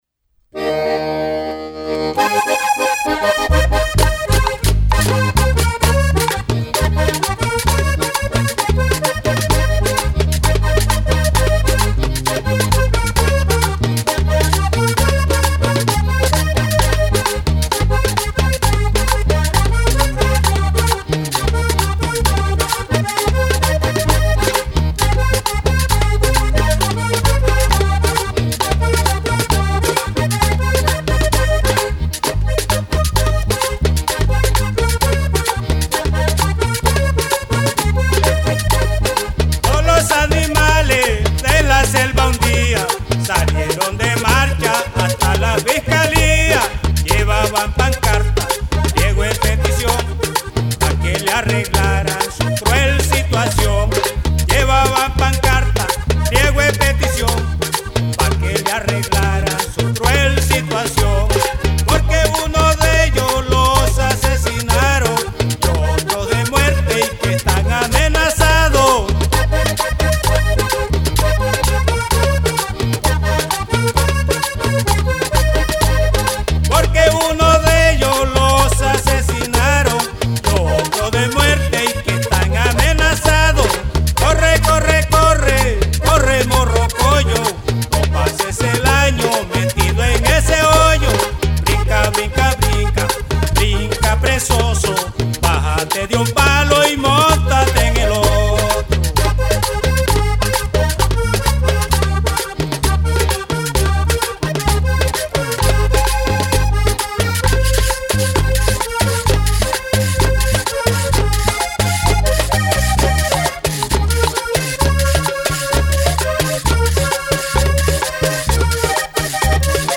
Canción
acordeón.
Caja.
guacharaca.